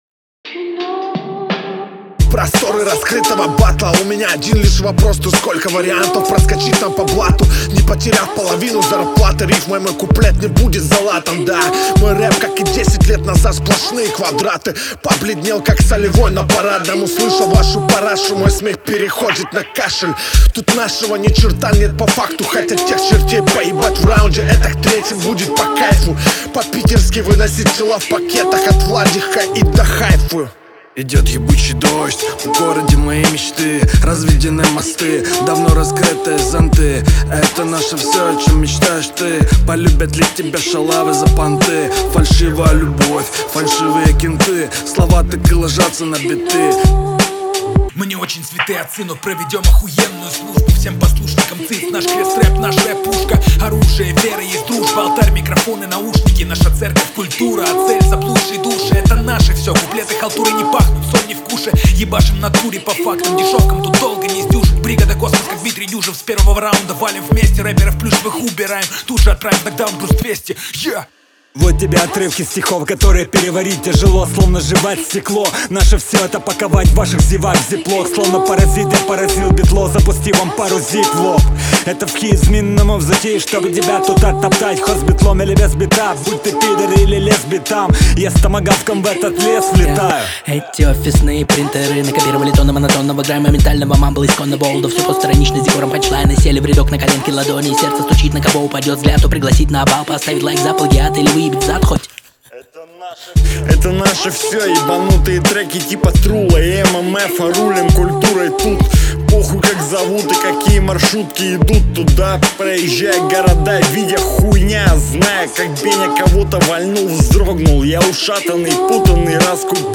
хоть читает а не жалобно скулит